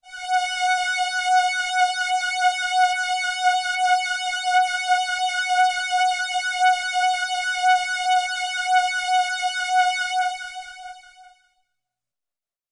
标签： midivelocity96 F6 midinote90 RolandJX3P synthesizer singlenote multisample
声道立体声